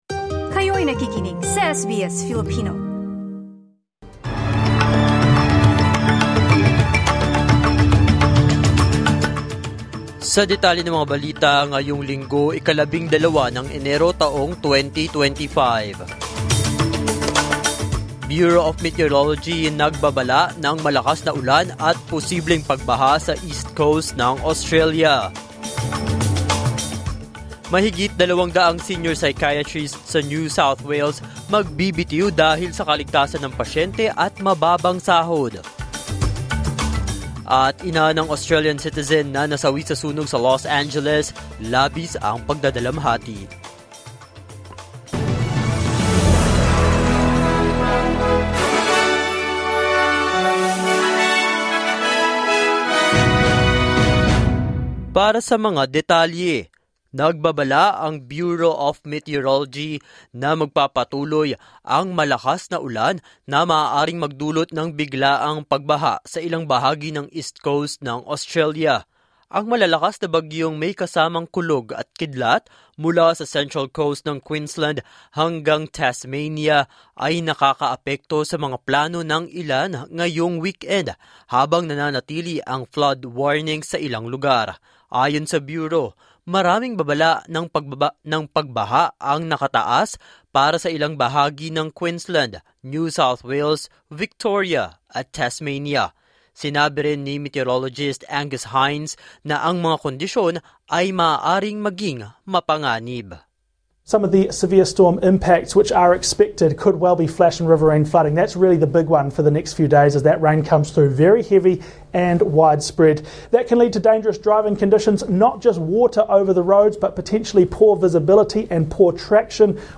SBS News in Filipino, Sunday 12 January 2025